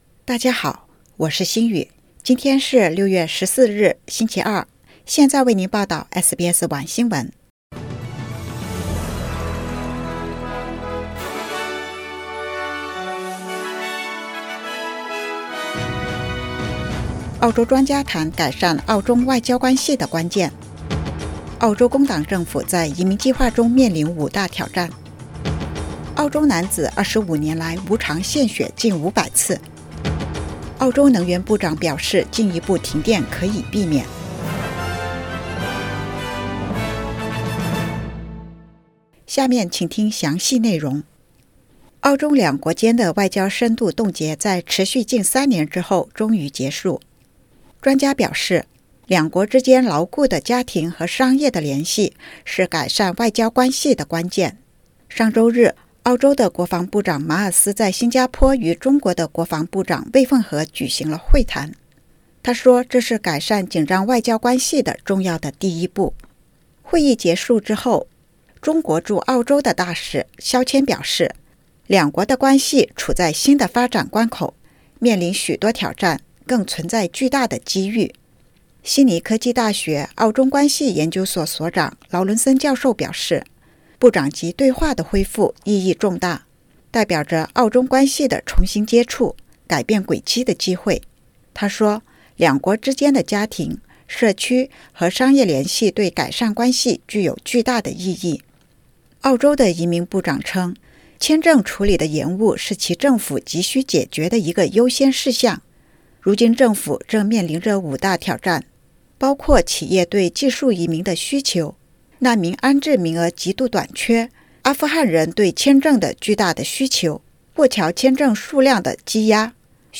SBS晚新闻（2022年6月14日）
SBS Mandarin evening news Source: Getty Images